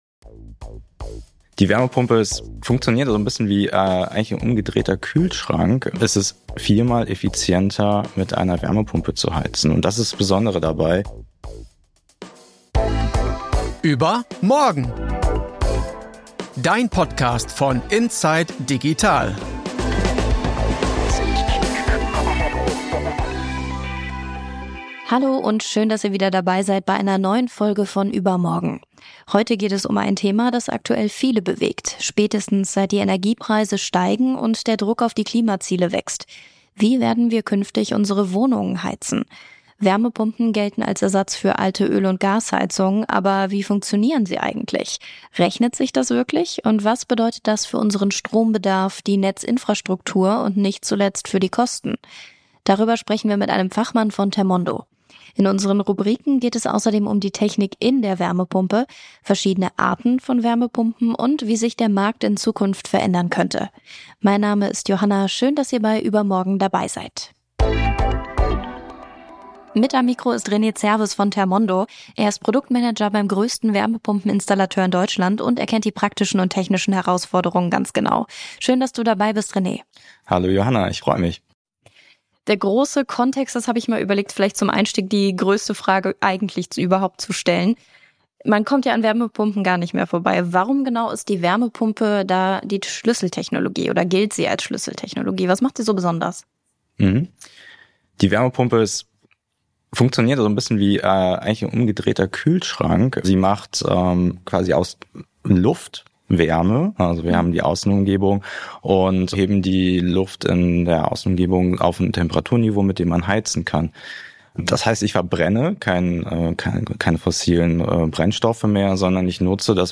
Und was bedeutet das für unseren Stromverbrauch, für die Netze und für den Geldbeutel? Wir sprechen mit einem Experten von Thermondo, einem Unternehmen, das sich auf den Einbau von Wärmepumpen spezialisiert hat. In den Rubriken geht es außerdem um die Technik in der Wärmepumpe, verschiedene Arten und wie heizen in zehn Jahren aussehen könnte.